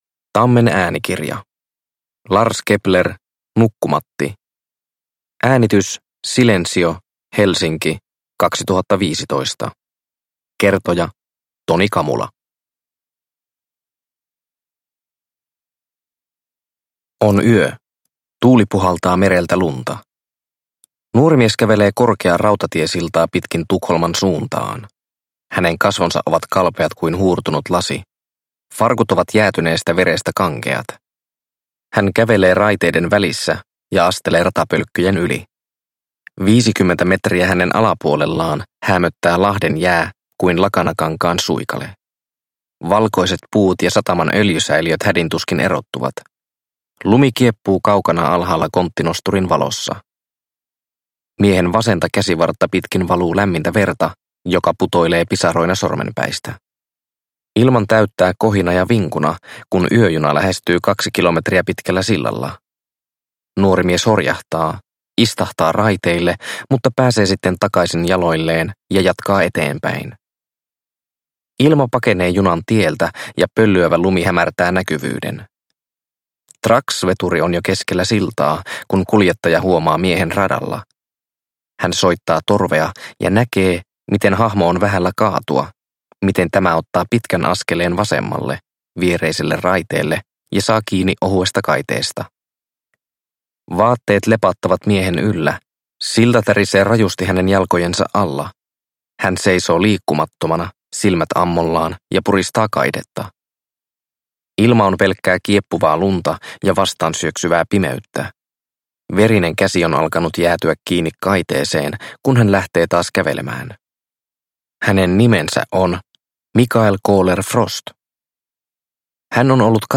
Nukkumatti – Ljudbok – Laddas ner